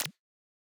generic-select-soft.wav